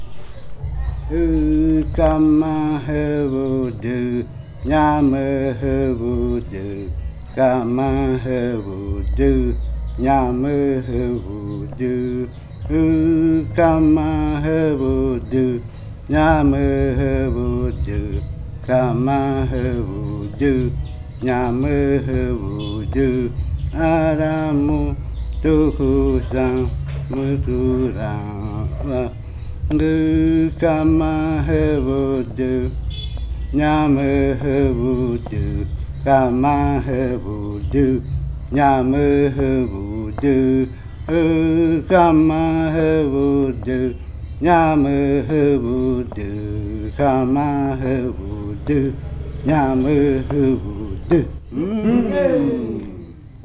Leticia, Amazonas, (Colombia)
Grupo de danza Kaɨ Komuiya Uai
Canto de fakariya de la variante muinakɨ en lengua ocaina (ɨvuza). La letra del canto significa benona kaɨmare jayede (aquí huele sabroso).
Fakariya chant of the muinakɨ variant (Downriver chants) in the Ocaina language (ɨvuza).